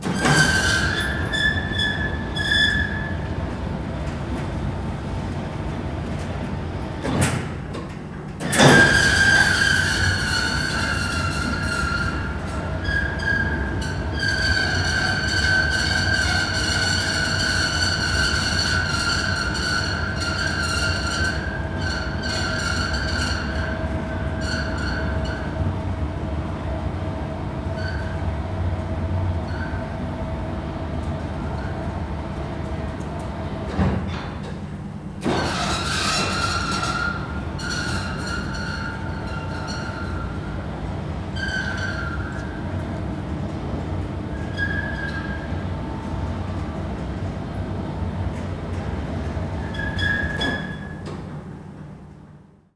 crane.R.wav